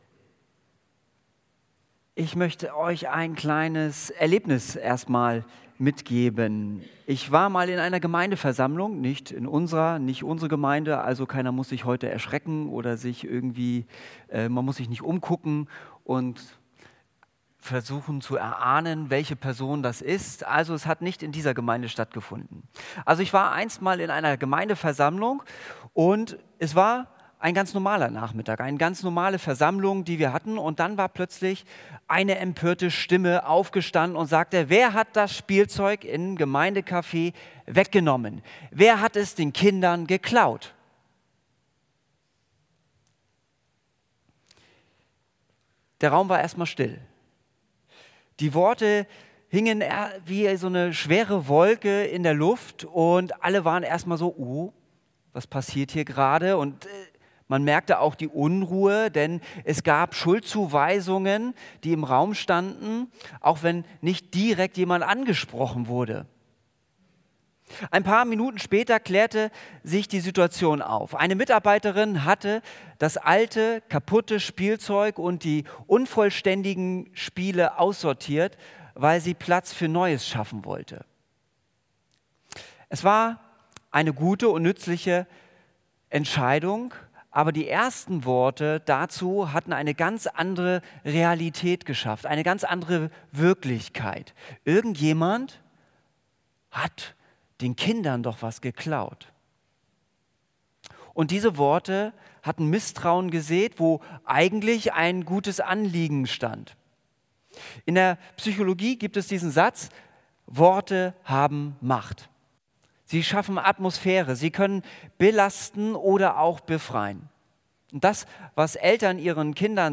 Predigt Wenn Gott spricht, dann...